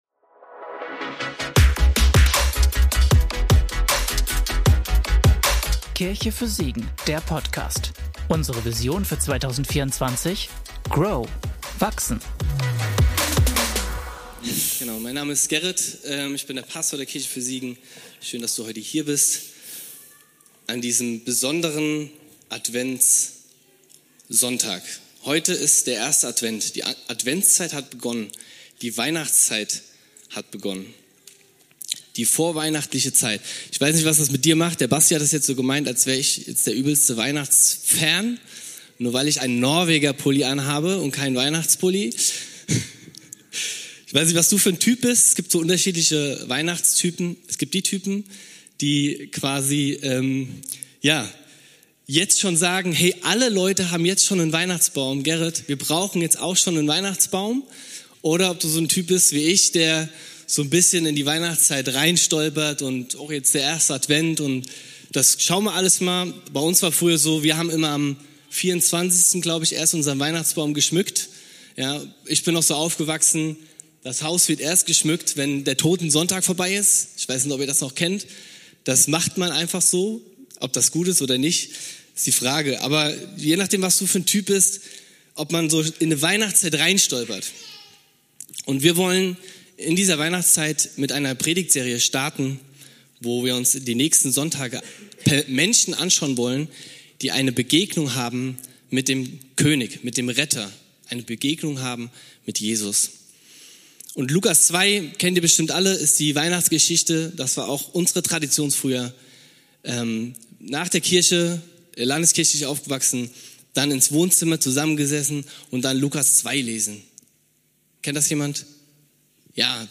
Maria - Predigtpodcast